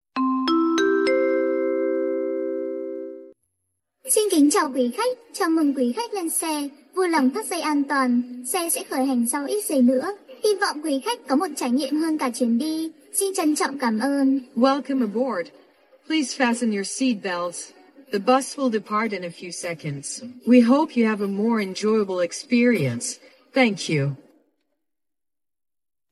Âm Thanh Chào Carplay
Thể loại: Tiếng chuông, còi
Description: Tải ngay Âm Thanh Chào CarPlay, sound CarPlay chào giọng nữ, Tiếng Việt và Tiếng Anh MP3, đoạn âm thanh chào khách, chủ nhân xe ô tô đang hot trend và viral trên TikTok!
am-thanh-chao-carplay-www_tiengdong_com.mp3